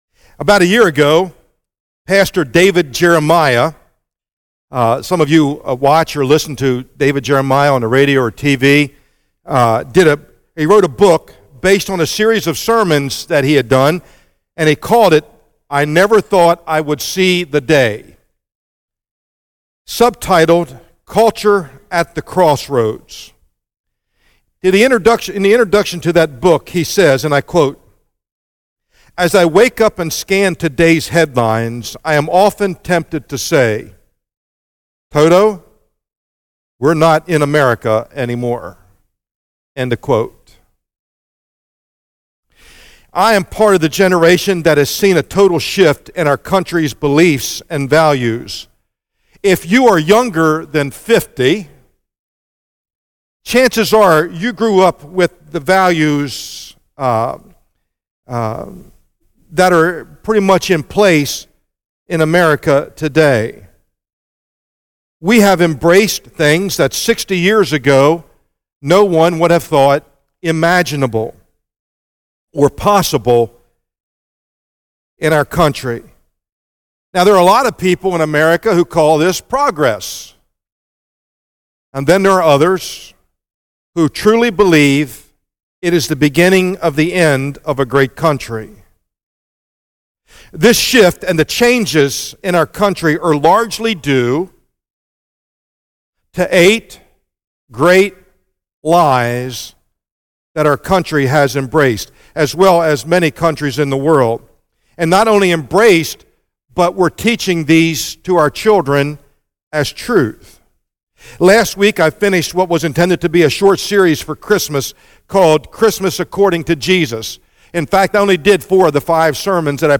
sermon text: 2 Timothy 2:14-26